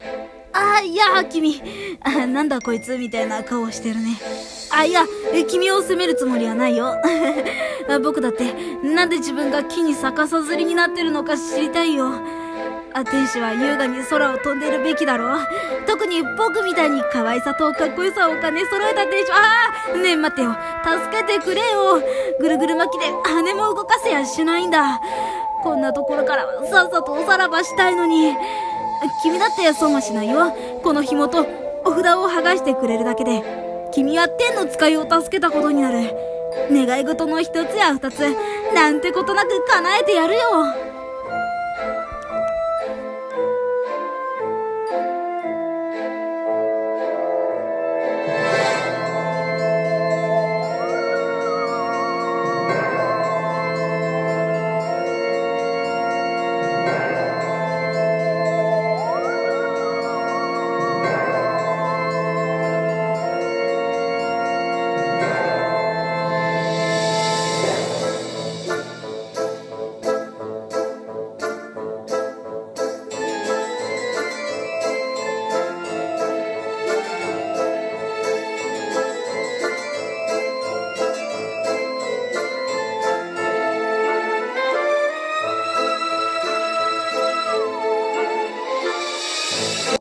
【1人声劇】逆さ吊りの天使